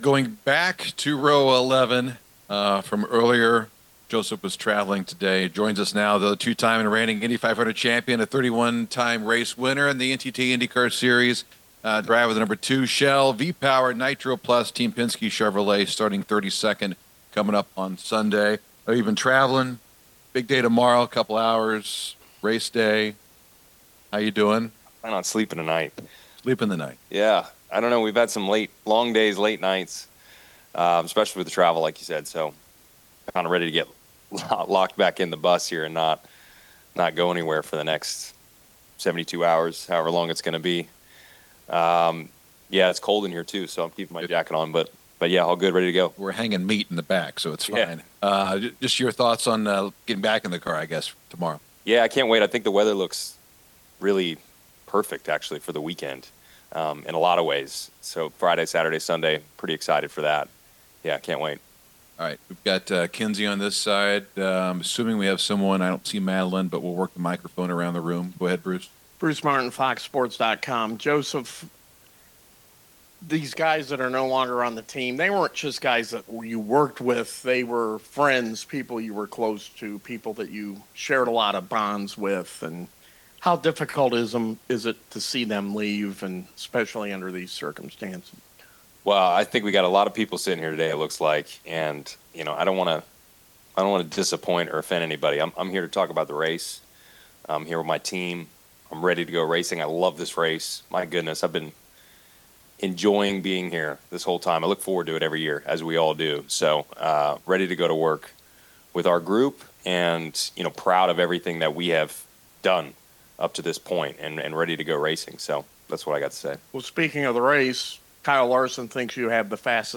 2025 Indianapolis 500 Drivers Media Day Press Conference: 2-Time Defending Champion Josef Newgarden answering questions about a 3-peat and the firings at Team Penske in the past week - Mega Sports News